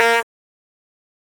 honk.ogg